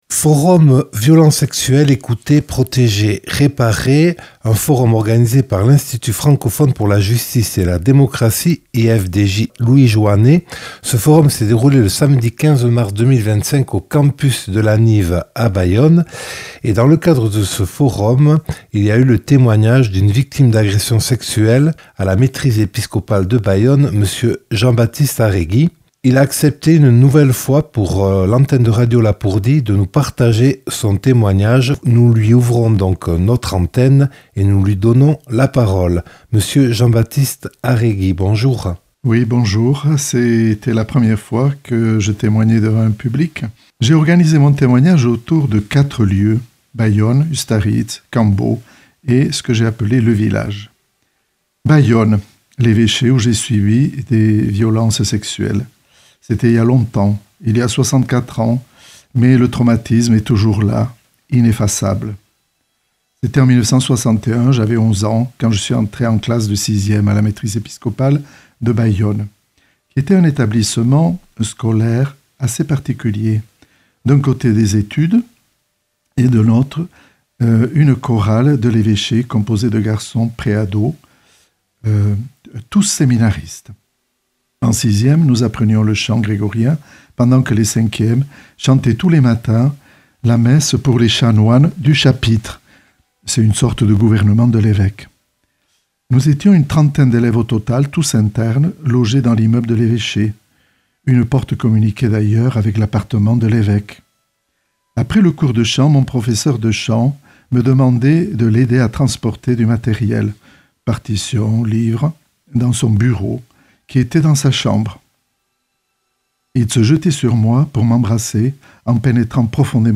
(Enregistré le 15/03/2025 au Campus de la Nive à Bayonne).